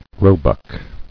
[roe·buck]